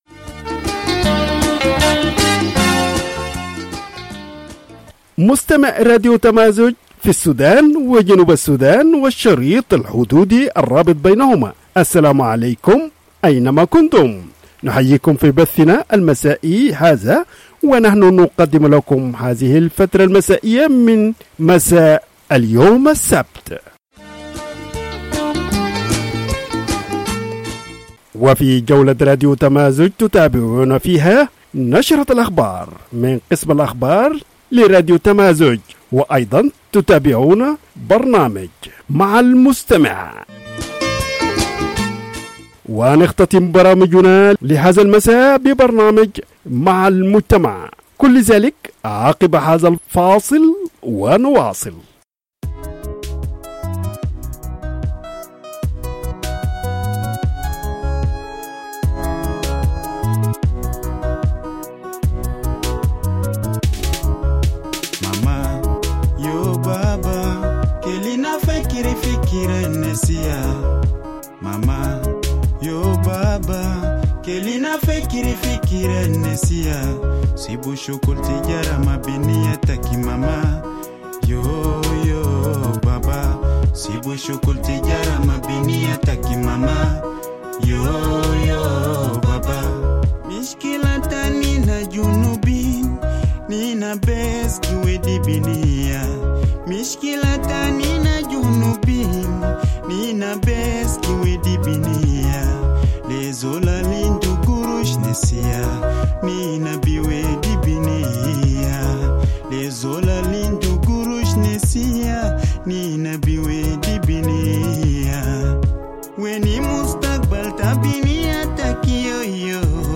Evening Broadcast 23 July - Radio Tamazuj